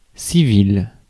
Ääntäminen
France: IPA: /si.vil/